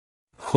Audio of the phoneme for Commonscript letter 36 (pronounced by male).
Phoneme_(Commonscript)_(Accent_0)_(36)_(Male).mp3